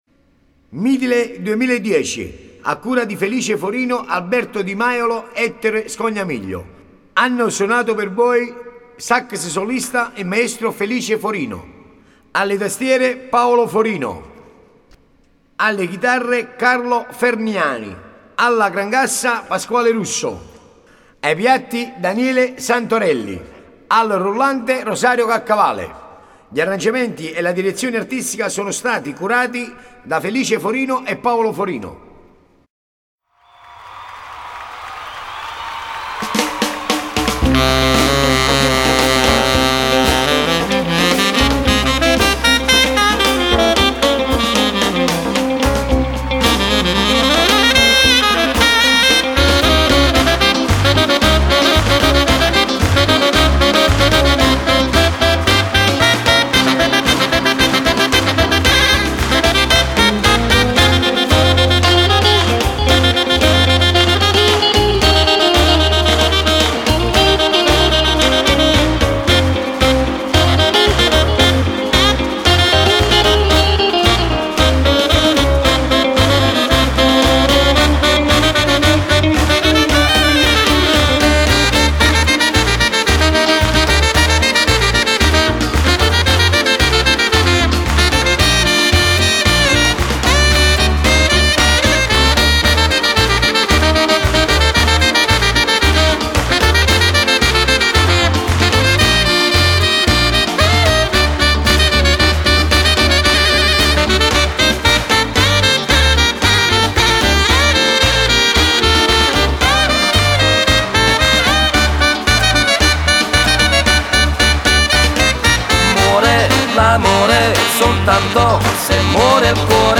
Canzone d'occasione